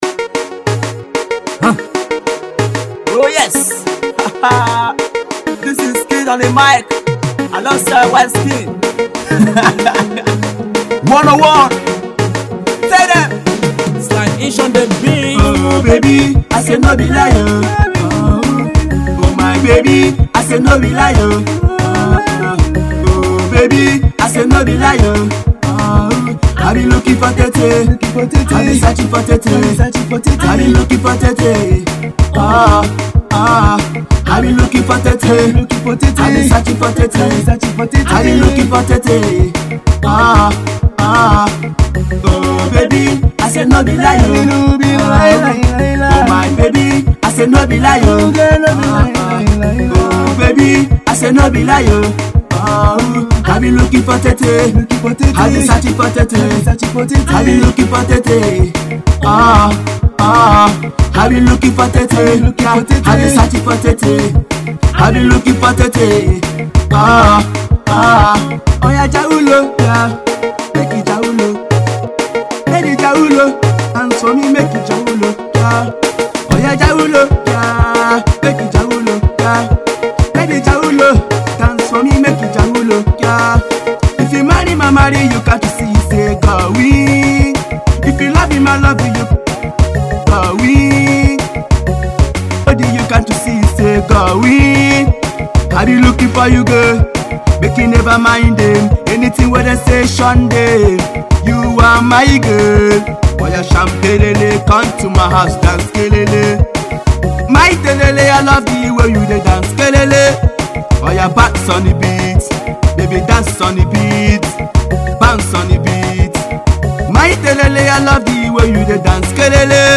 love song
upcoming music duo